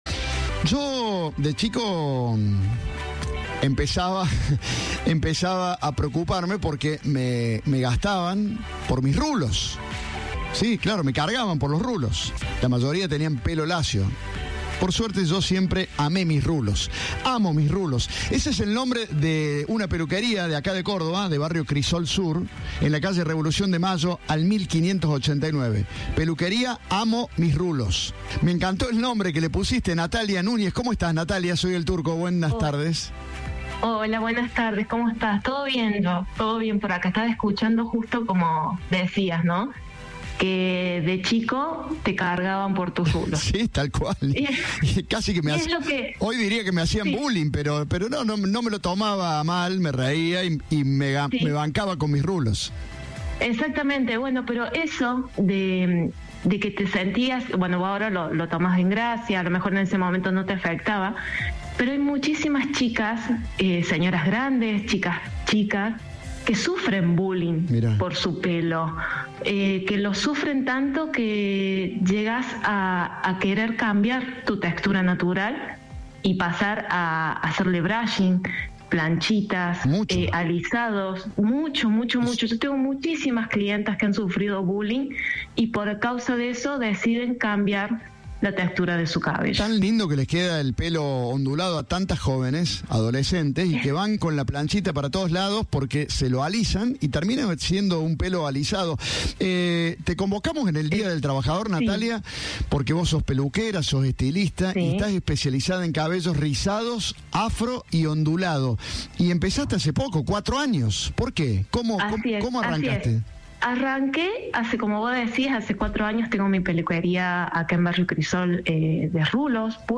El fiscal a cargo de Delitos Complejos habló sobre la detención del subjefe de Policía. Negó ceder a presiones políticas.